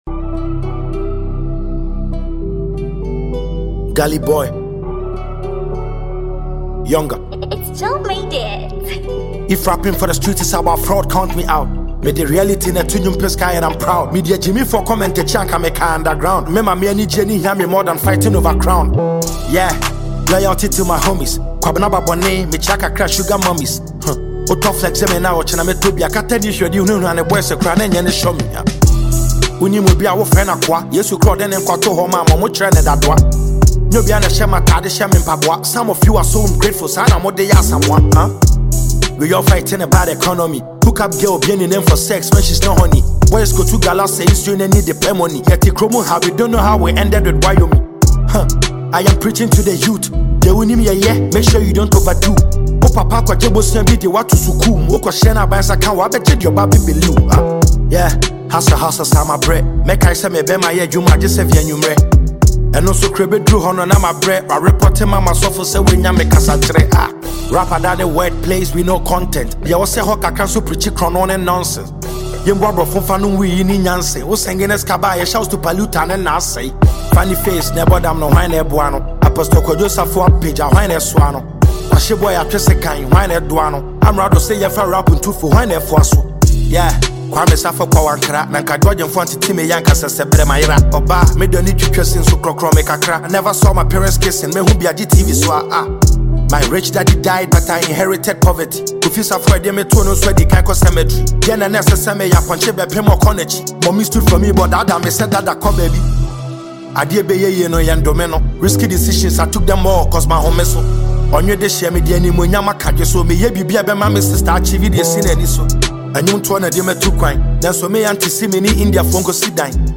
This track is a must-listen for fans of authentic hip-hop.